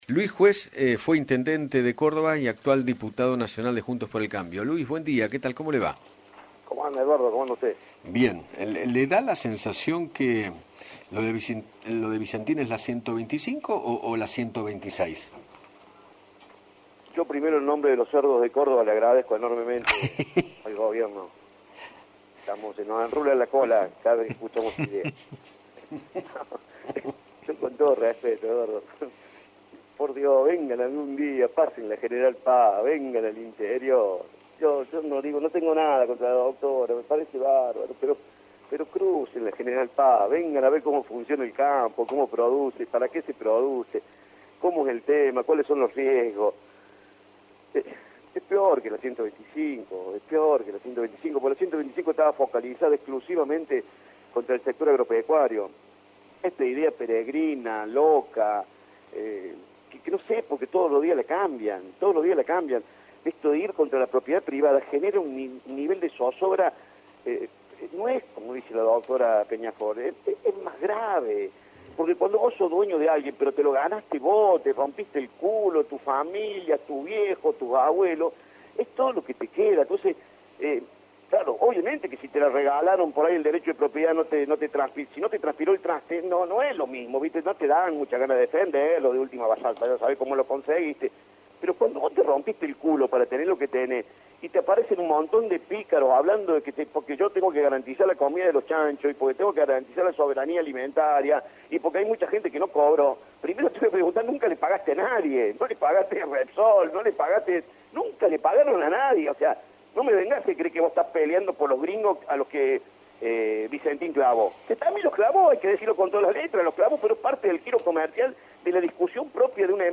Luis Juez, ex intendente de la ciudad de Córdoba y actual diputado nacional, dialogó con Eduardo Feinmann sobre la intervención de Vicentin y se refirió al plan del gobernador de Santa Fe, Omar Perotti.